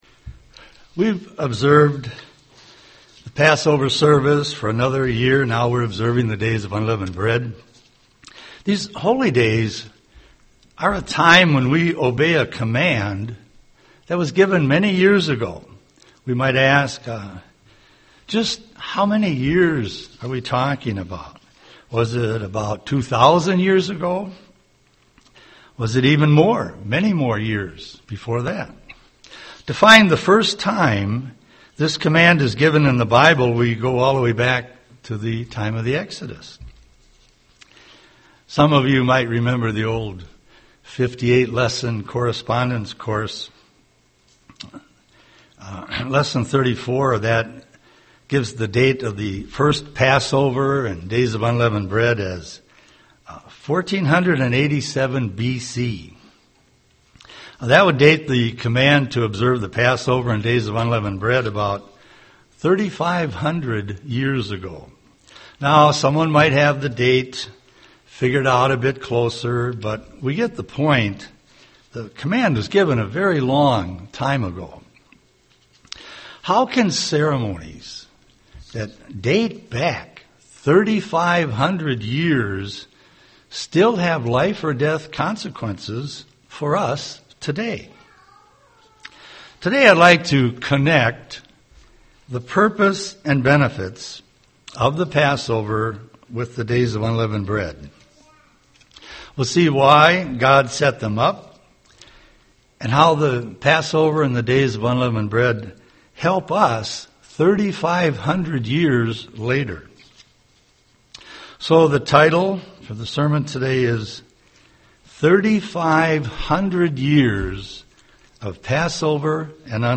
Given in Twin Cities, MN
UCG Sermon Unleavened Bread Passover Studying the bible?